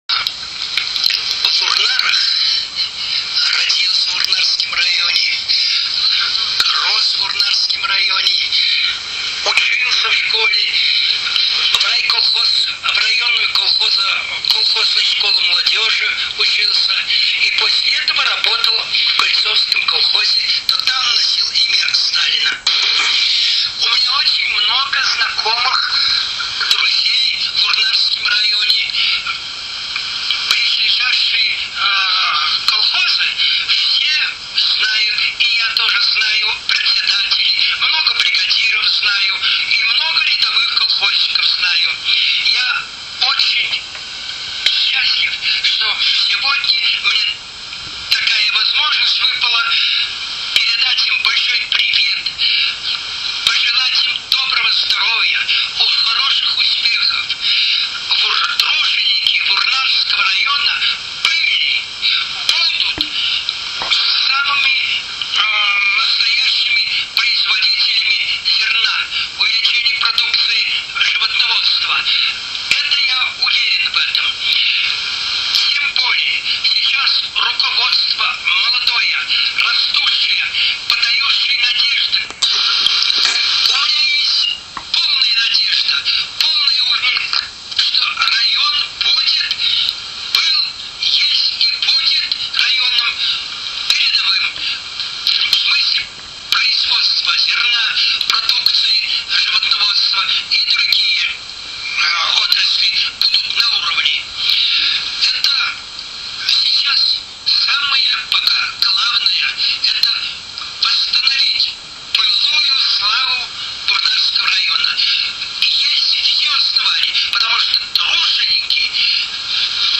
Архивный материал. Аудиозапись обращения